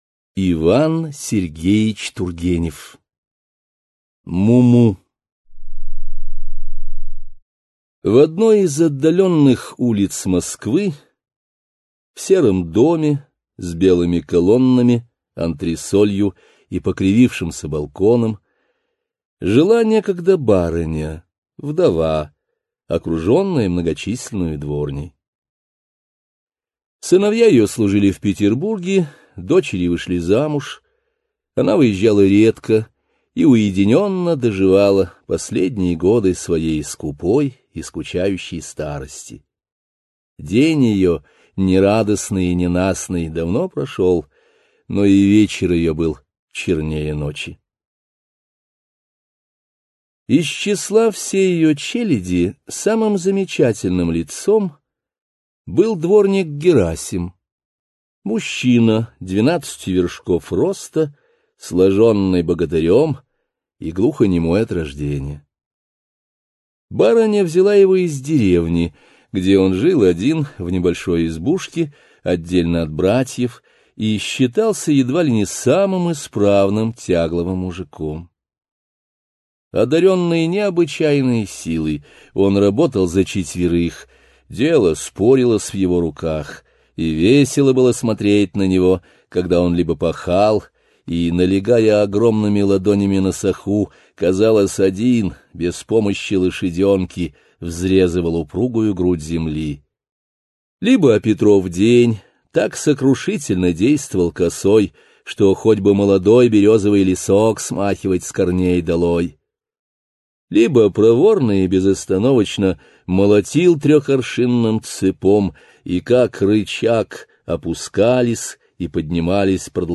Аудиокнига Муму | Библиотека аудиокниг